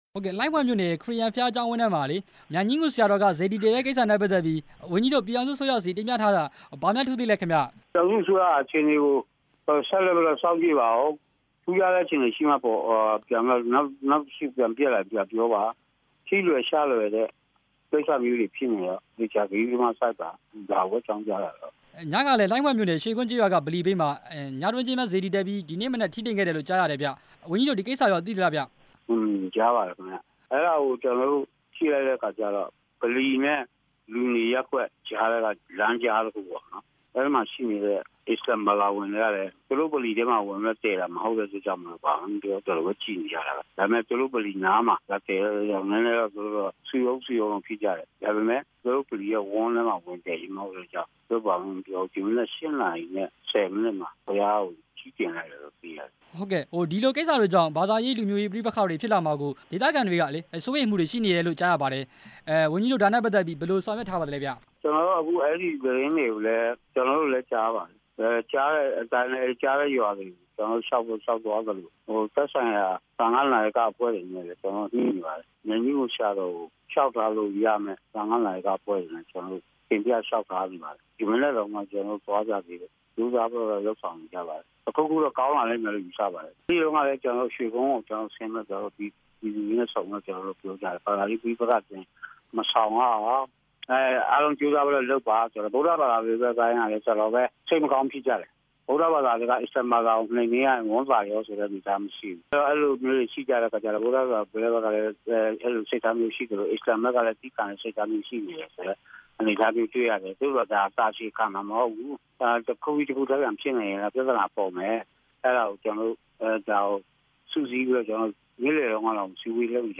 စေတီတွေတည်တဲ့ကိစ္စ ကရင်ပြည်နယ် သာသနာရေး ဝန်ကြီးနဲ့ မေးမြန်းချက်